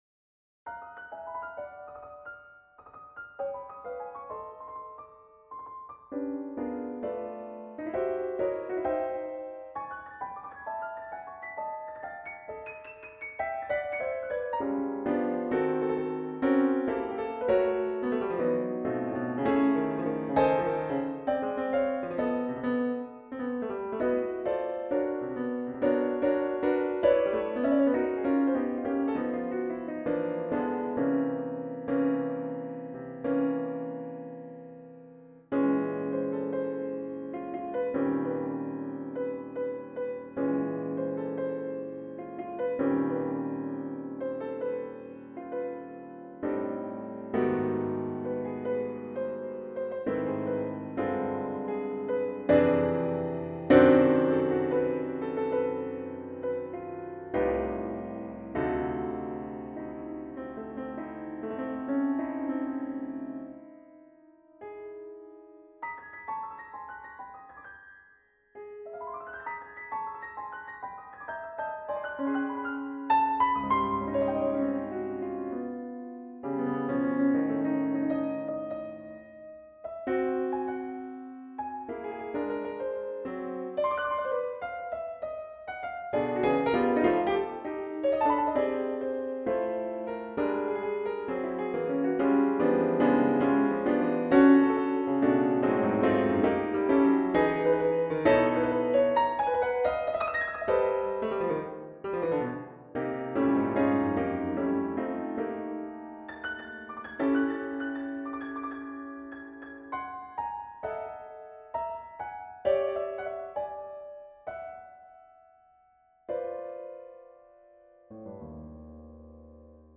Unperformed work, so just computer realisations (my apologies).